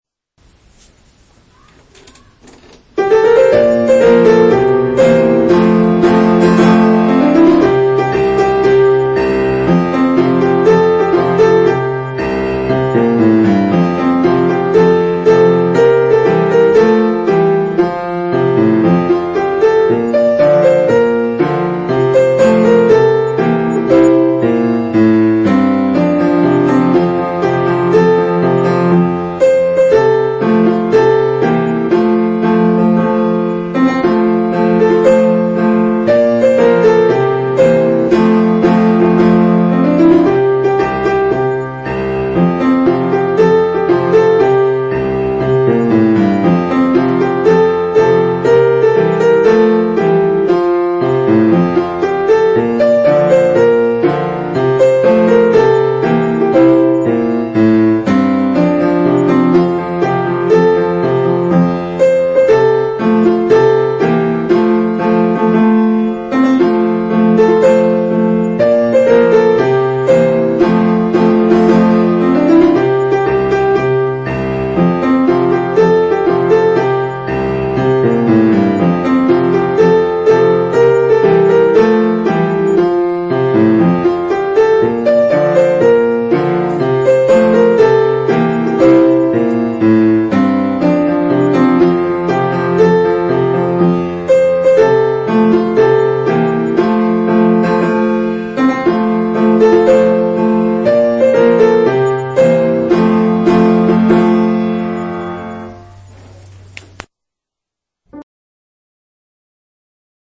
伴奏.mp3